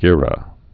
(gîrə)